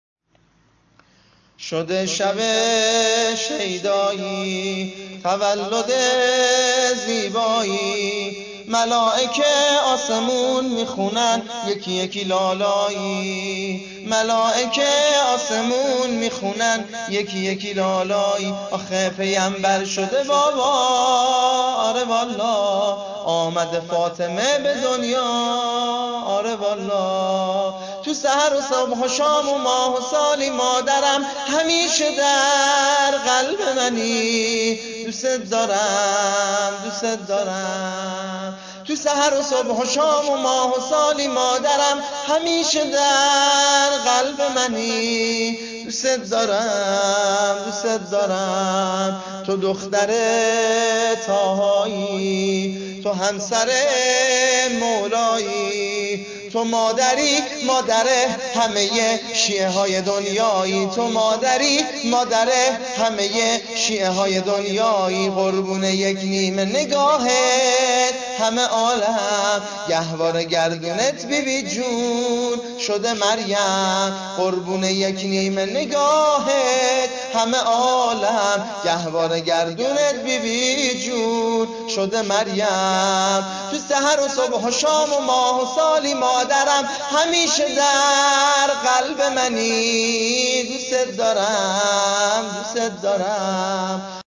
عنوان : دانلود سرود میلاد حضرت زهرا (س)